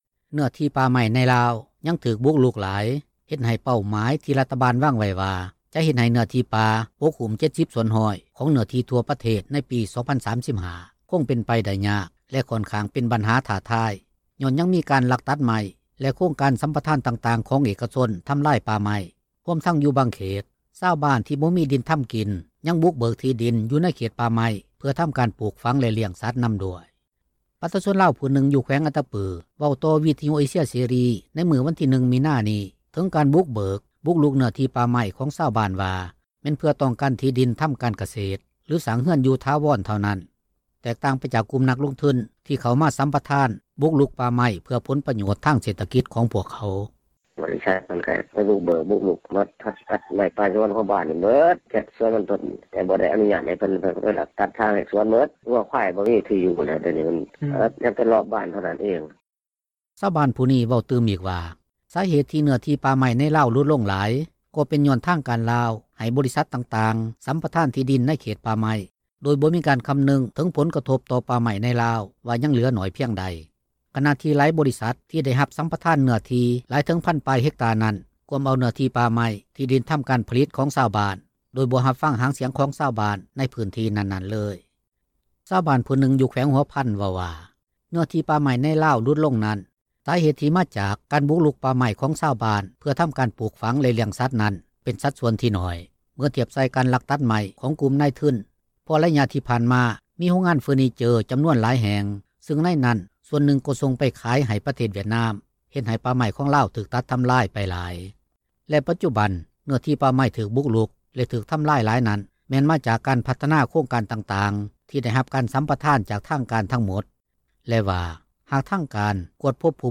ປະຊາຊົນລາວຜູ້ນຶ່ງ ຢູ່ແຂວງອັດຕະປື ເວົ້າຕໍ່ວິທຍຸ ເອເຊັຽ ເສຣີ ໃນມື້ວັນທີ 1 ມິນານີ້ ເຖິງການບຸກເບີກ ບຸກລຸກເນື້ອທີ່ປ່າໄມ້ ຂອງຊາວບ້ານວ່າ ແມ່ນເພື່ອຕ້ອງການ ທີ່ດິນທໍາການກະເສດ ຫລືສ້າງເຮືອນຢູ່ຖາວອນ ເທົ່ານັ້ນ, ແຕກຕ່າງໄປຈາກ ກຸ່ມນັກລົງທຶນ ທີ່ເຂົ້າສັມປະທານ ບຸກລຸກປ່າໄມ້ເພື່ອຜົລປໂຍດ ທາງທຸຣະກິຈ ຂອງພວກເຂົາ.